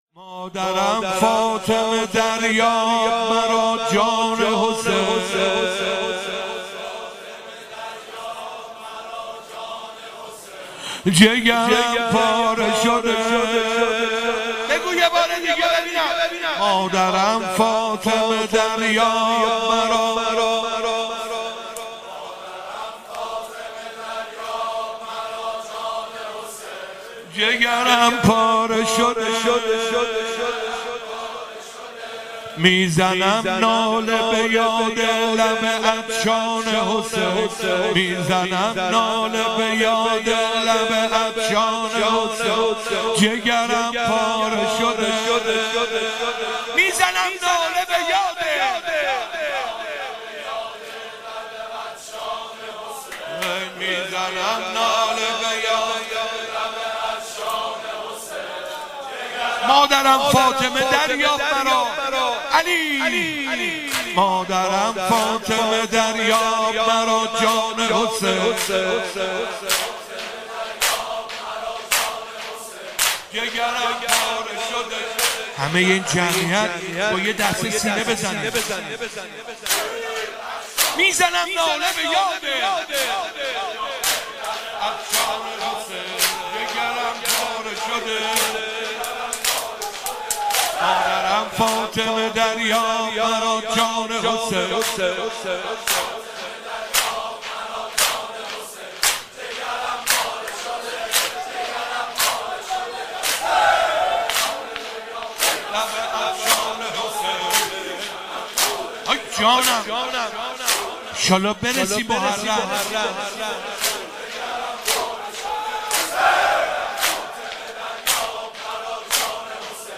شهادت امام صادق (ع)